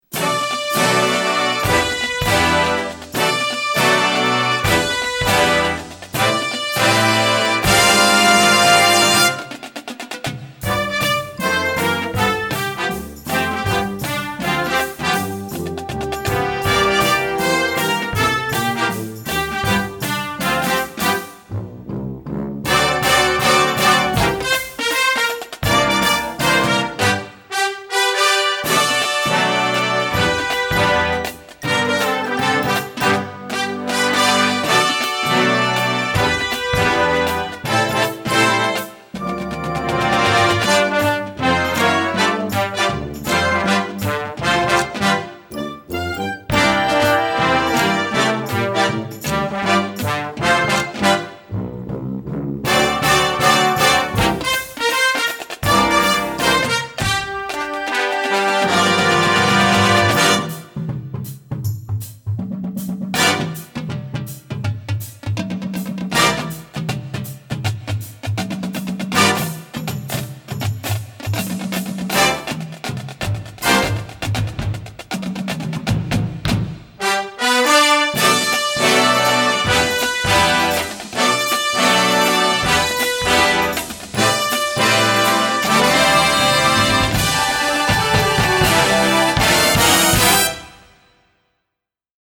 Gattung: Marching Band Series
Besetzung: Blasorchester